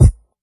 '00s Dry Bass Drum Sound G# Key 289.wav
Royality free kick sound tuned to the G# note. Loudest frequency: 393Hz
00s-dry-bass-drum-sound-g-sharp-key-289-IFT.ogg